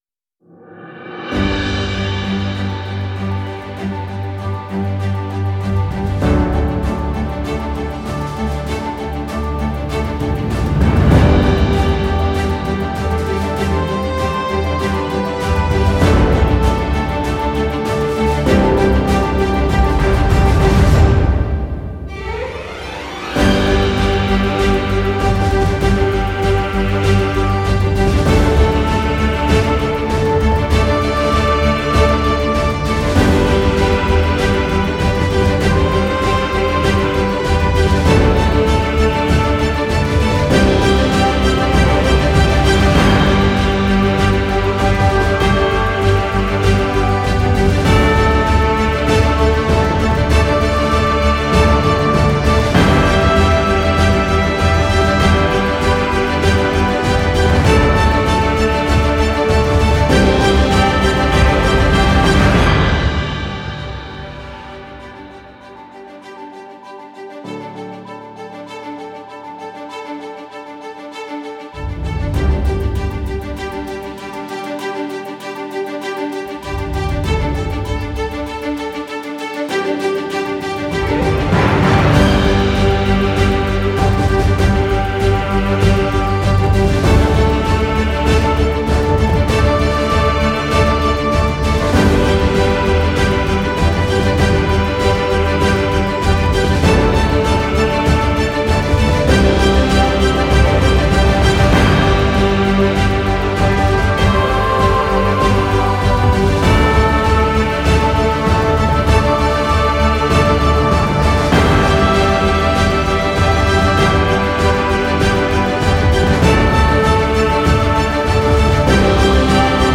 Genre: filmscore, classical.